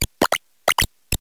maushold_ambient.ogg